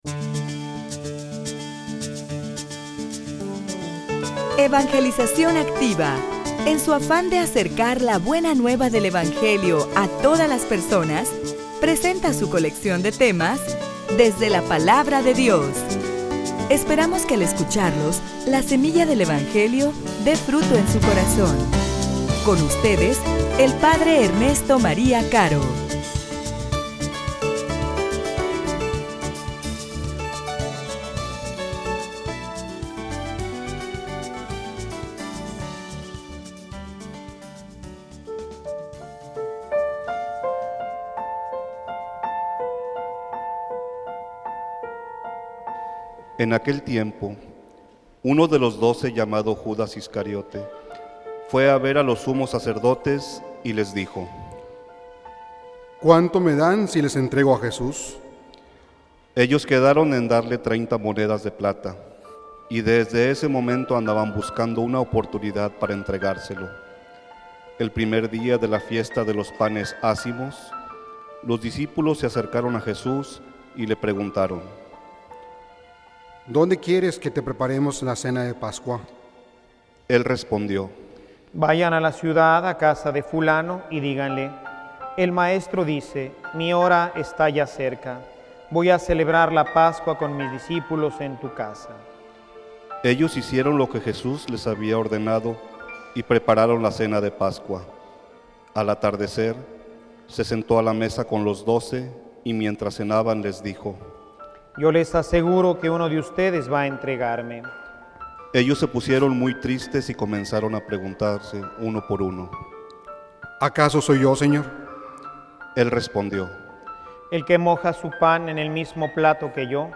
homilia_Tu_tambien_lo_crucificaste.mp3